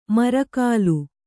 ♪ mara kālu